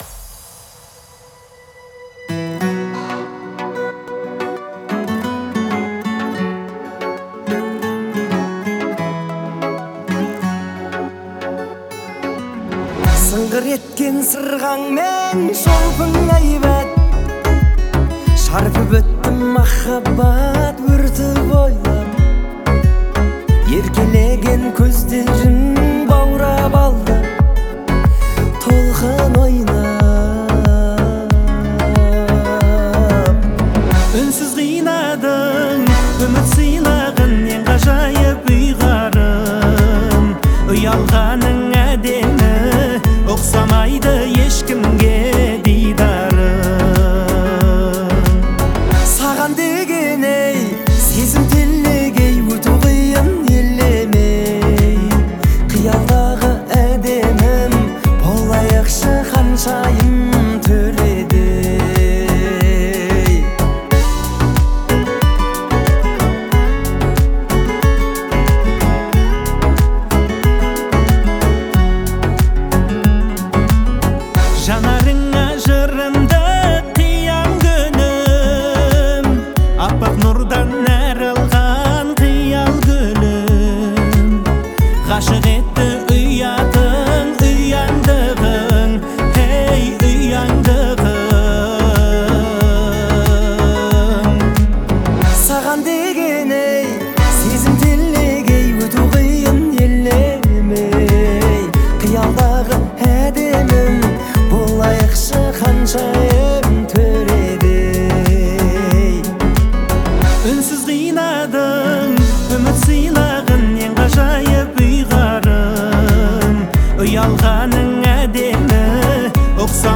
• Жанр: New Kaz / Казахские песни